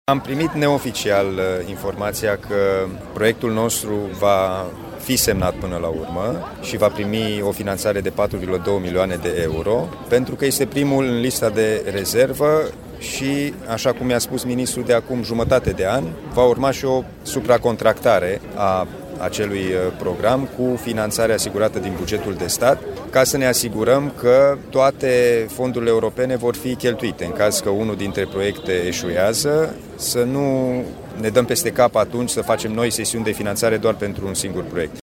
Președintele CJ Timiș, Alin Nica, spune că a primit neoficial informația potrivit căreia proiectul județului va fi semnat în cele din urmă și va veni finanțarea de 5 milioane de euro.
Alin-Nica-Huniade-PNRR.mp3